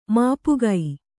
♪ māpugai